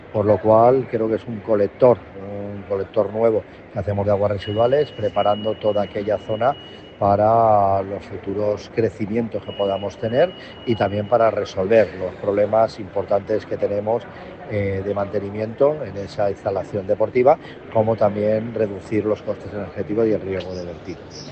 Declaraciones Joserra González de Zárate 👇